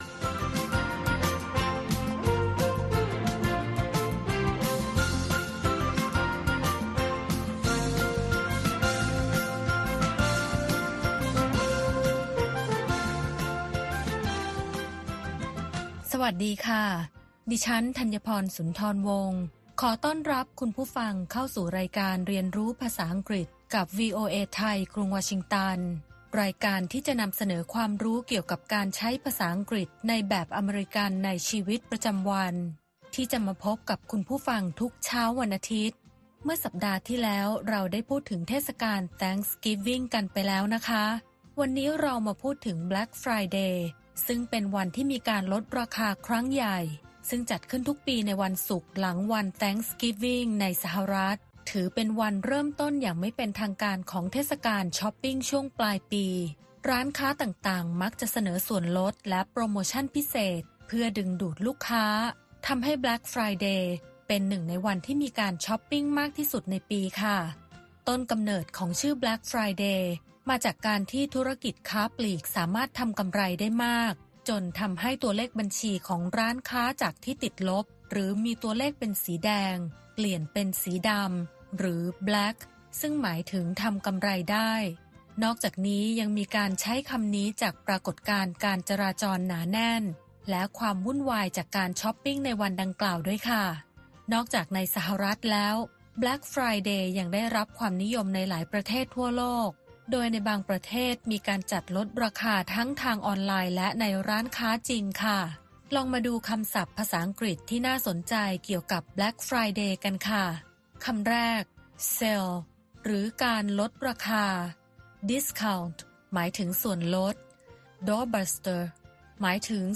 เรียนภาษาอังกฤษจากแบบเรียนที่จัดทำตามมาตรฐาน มีตัวอย่างการใช้ และการออกเสียงจากผู้ใช้ภาษาโดยตรง